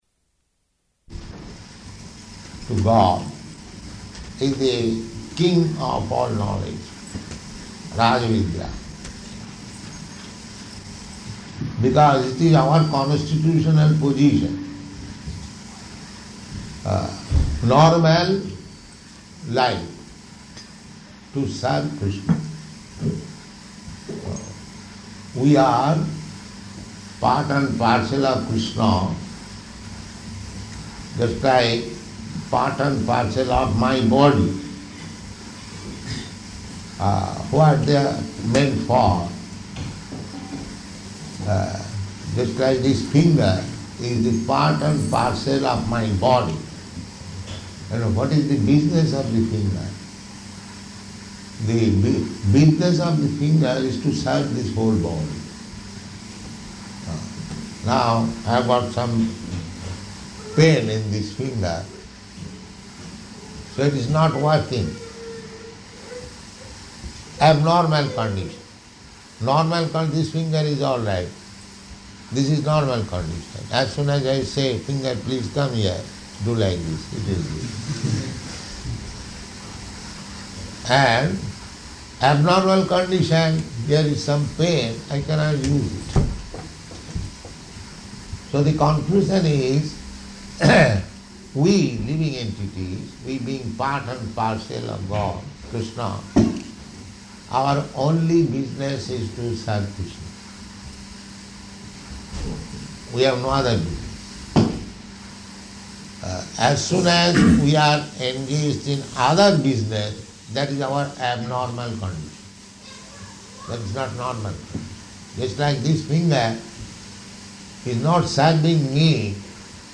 Lecture
Lecture --:-- --:-- Type: Lectures and Addresses Dated: February 14th 1973 Location: Sydney Audio file: 730214LE.SYD.mp3 Prabhupāda: ...to God it is the king of all knowledge, rāja-vidyā.